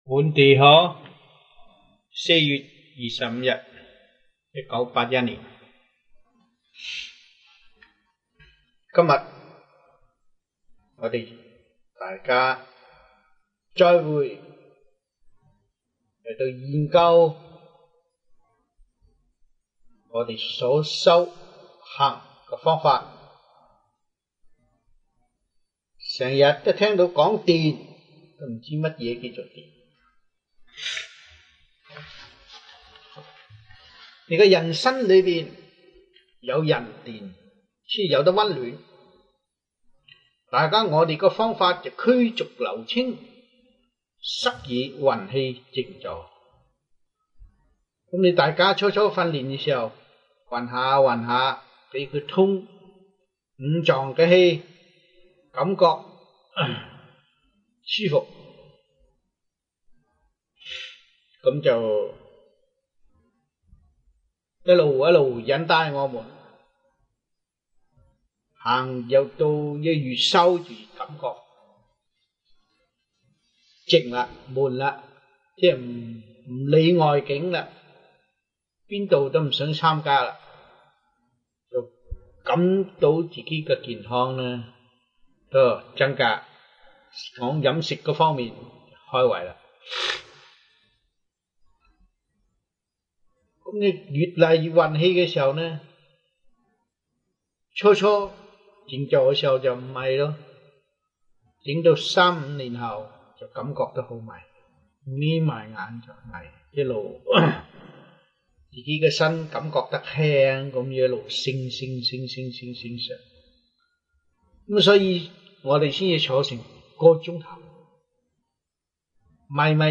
Lectures-Chinese-1981 (中文講座)